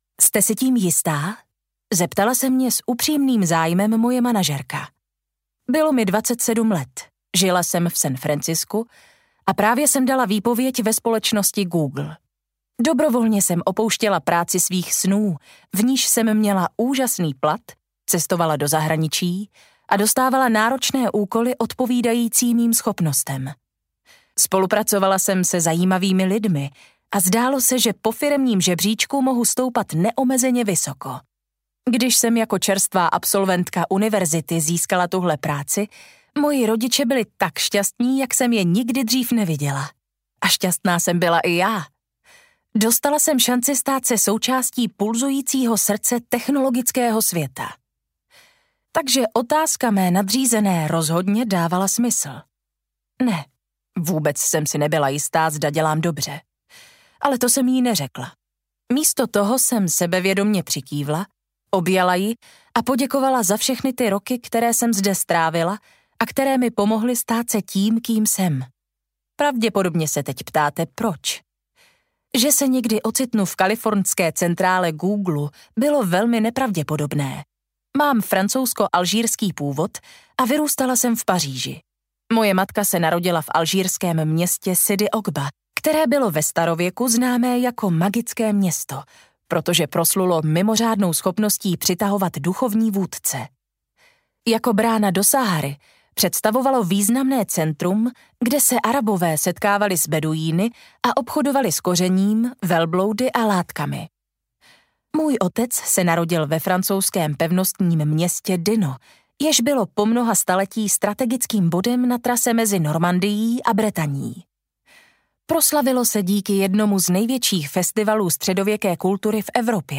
Audiokniha Malé experimenty - Anne - Laure Le Cunff | ProgresGuru